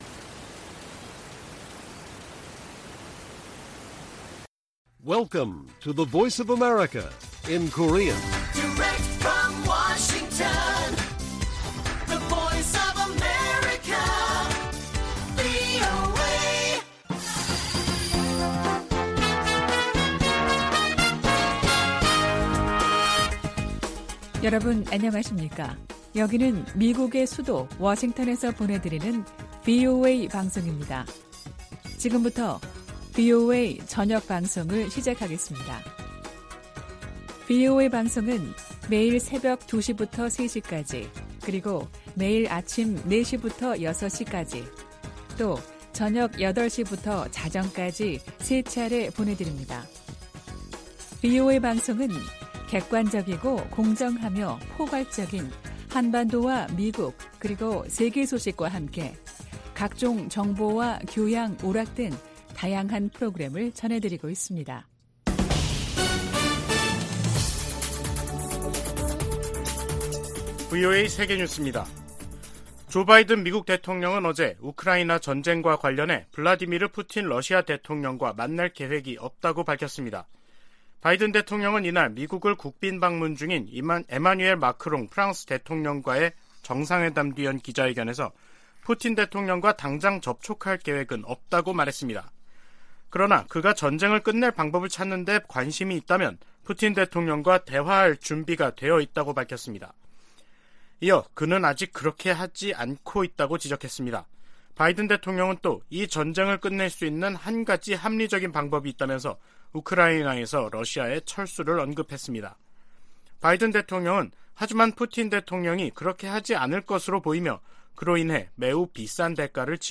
VOA 한국어 간판 뉴스 프로그램 '뉴스 투데이', 2022년 12월 2일 1부 방송입니다. 미국 정부가 북한 정권의 잇따른 탄도미사일 발사에 대응해 노동당 간부 3명을 제재했습니다. 한국 정부도 49일만에 다시 북한의 핵과 미사일 개발 등에 관여한 개인과 기관들을 겨냥해 독자 제재를 가하는 등 미한 공조 대응이 강화되고 있습니다.